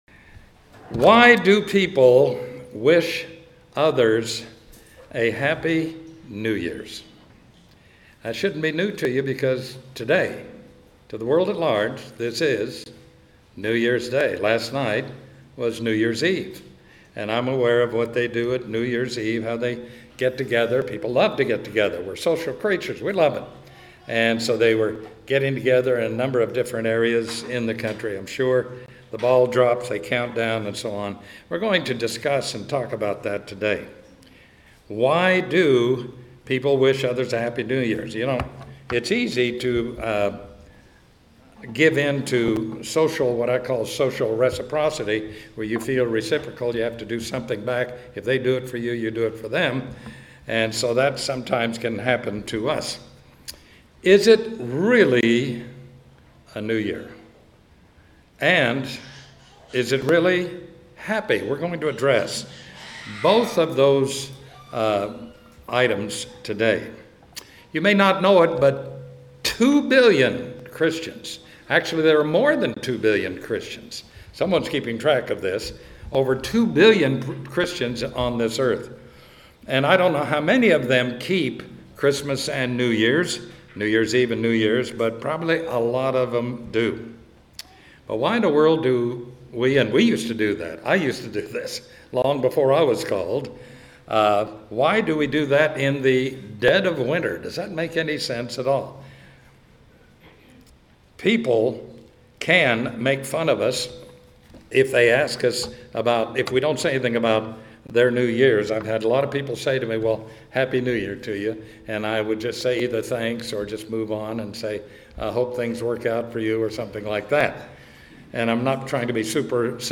Sermons
Given in Atlanta, GA Buford, GA